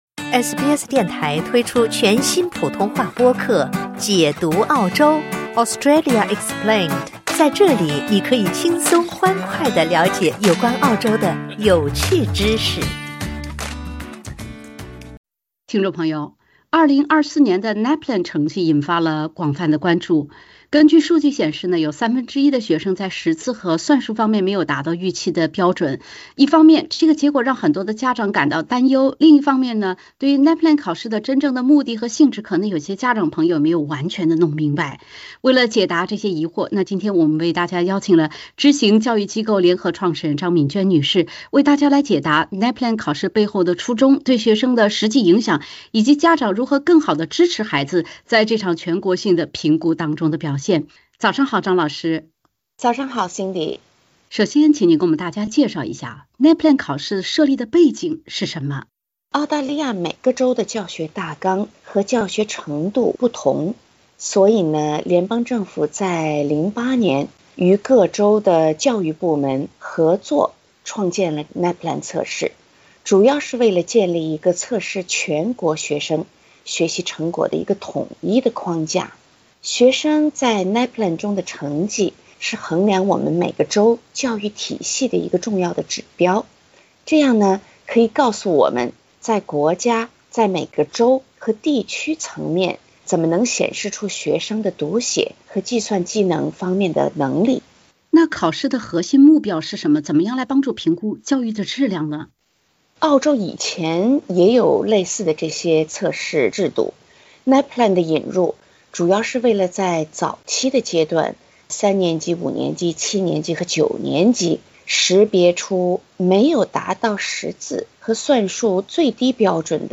教育人士解答NAPLAN考试背后的初衷、对学生的实际影响，以及家长如何更好地支持孩子在这场全国性评估中的表现。